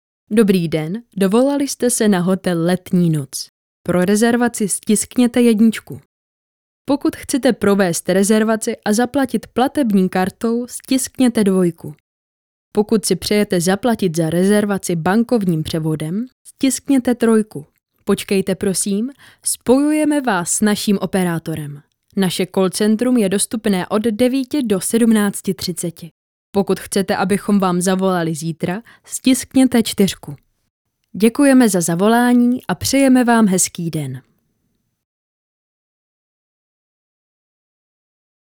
Ženský voiceover do reklamy / 90 sekund
Hledáte do svého videa příjemný ženský hlas?
Nahrávání probíhá v profesionálním studiu a výsledkem je masterovaná audio stopa ve formátu WAV, ořezaná o nádechy a další rušivé zvuky.